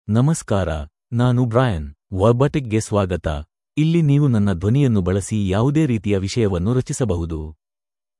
Brian — Male Kannada (India) AI Voice | TTS, Voice Cloning & Video | Verbatik AI
Brian is a male AI voice for Kannada (India).
Voice sample
Listen to Brian's male Kannada voice.
Brian delivers clear pronunciation with authentic India Kannada intonation, making your content sound professionally produced.